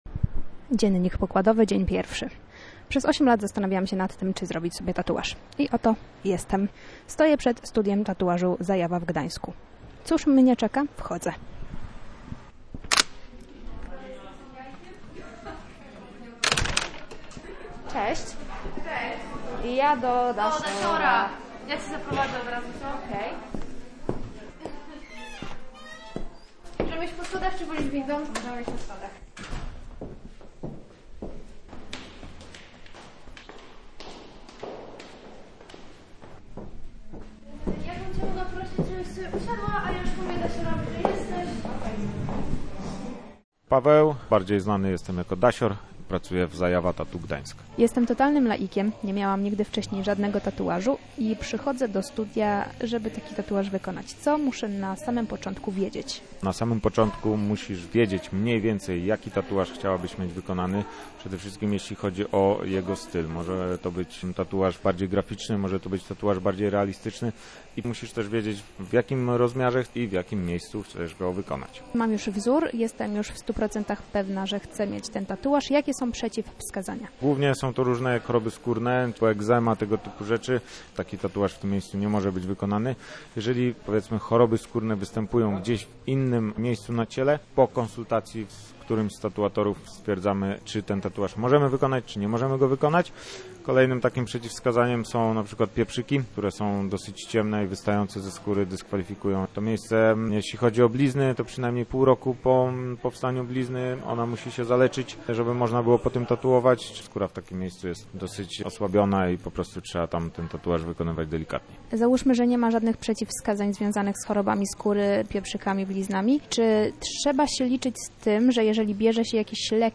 Kilkoro artystów, dziesiątki tysięcy wzorów i dźwięk maszynki. Wchodzimy z mikrofonem do studia tatuażu